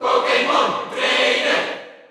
File:Pokémon Trainer Cheer Dutch SSBU.ogg
Pokémon_Trainer_Cheer_Dutch_SSBU.ogg.mp3